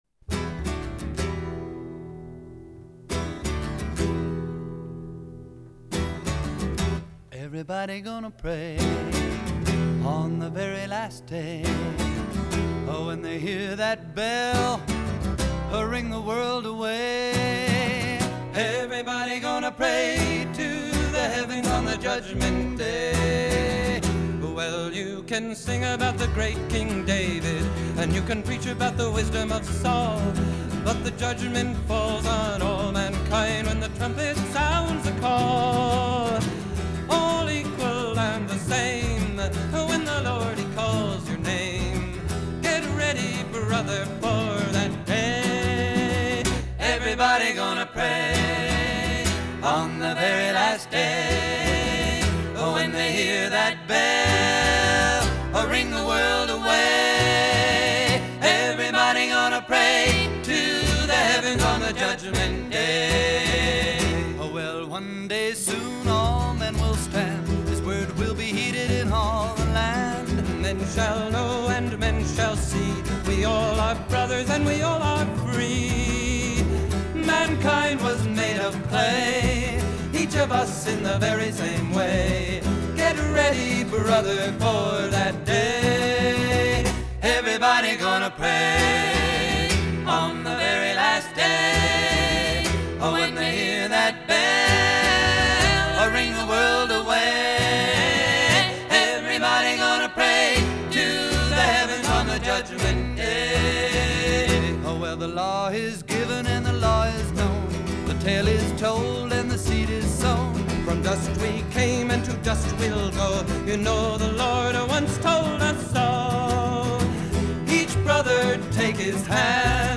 американского фолк-трио
вокал, гитара
бас